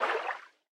Sfx_creature_symbiote_swim_slow_08.ogg